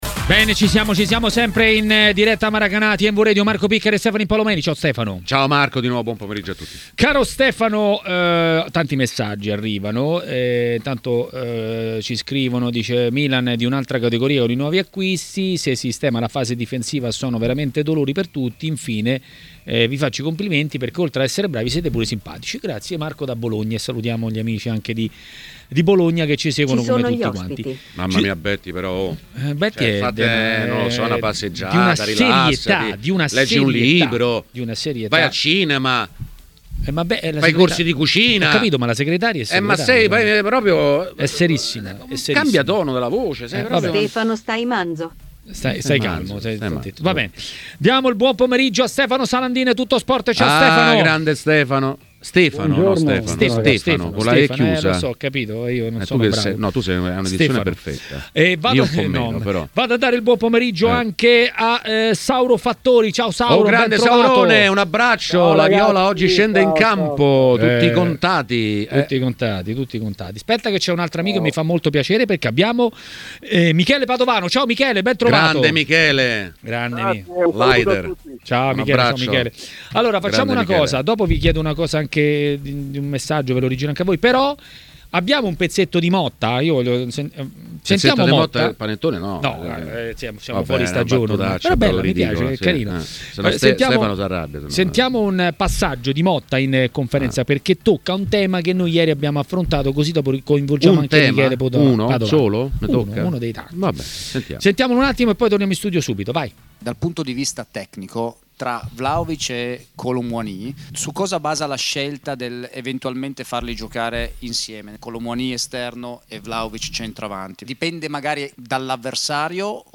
A parlare di Juve a Maracanà, nel pomeriggio di TMW Radio, è stato l'ex attaccante Michele Padovano.